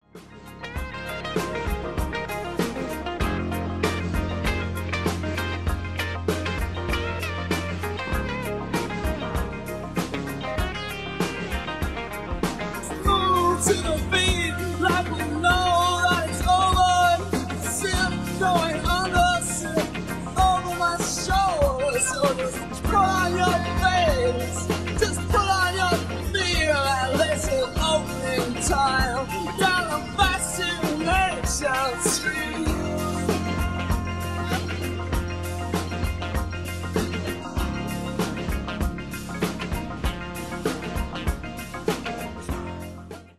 mashups of other people's songs